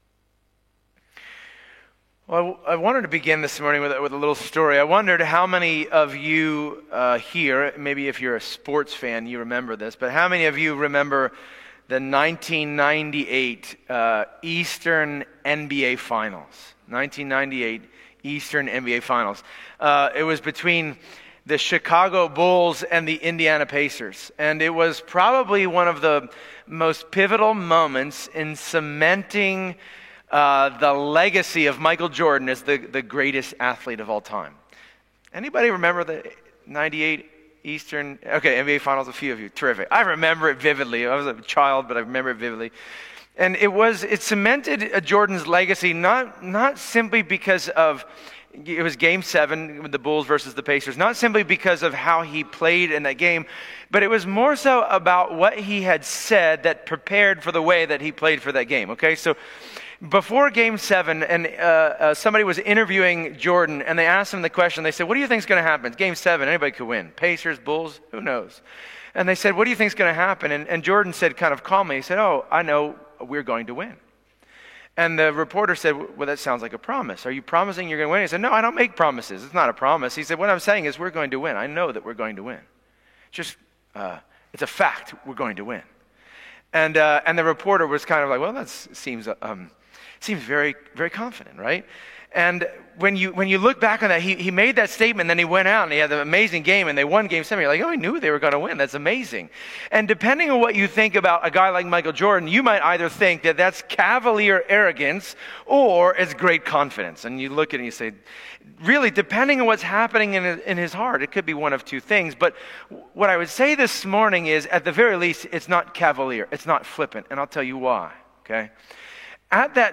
Passage: Habakkuk 3 Service Type: Sunday Morning